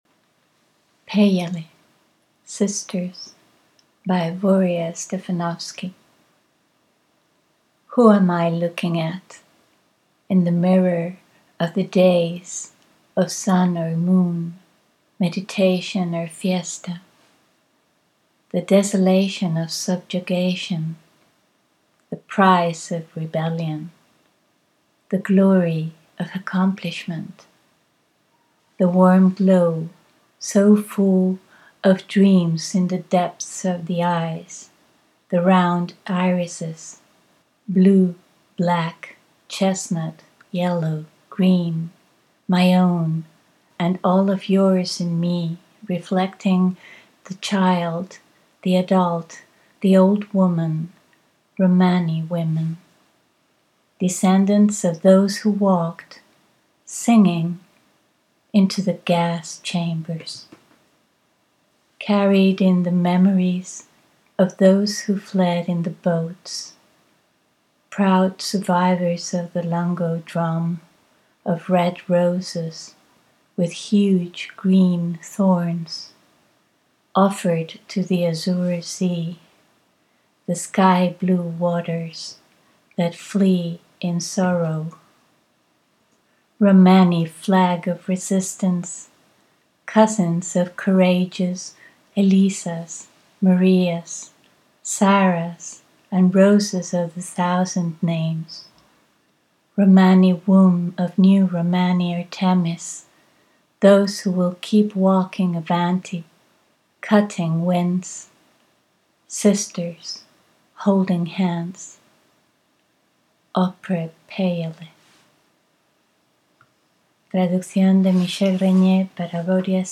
Escuchar Escuchar el poema en voz de su autora